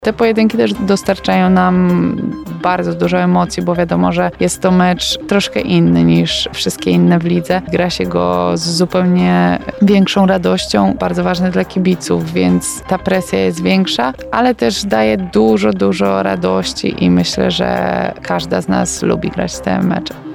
Opublikowano w Aktualności, Audycje, Poranna Rozmowa Radia Centrum, Sport